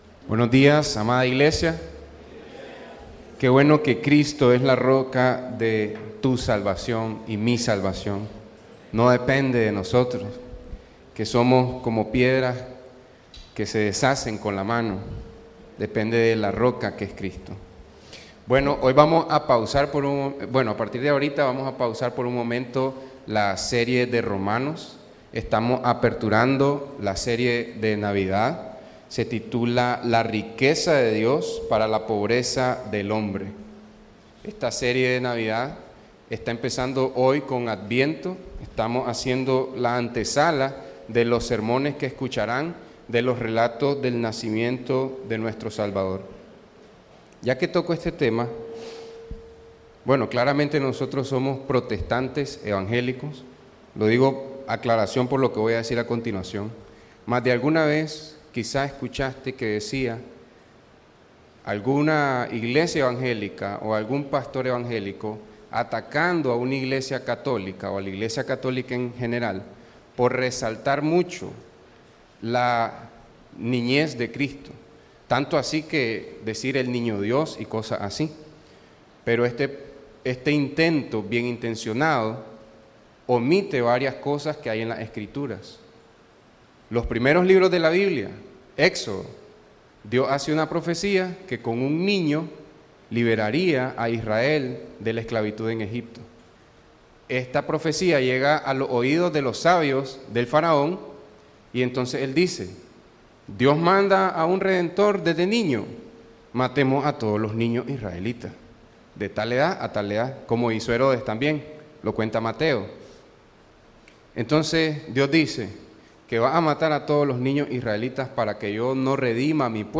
¿Cuánto confías en el brazo poderoso de Dios? Escucha la prédica del domingo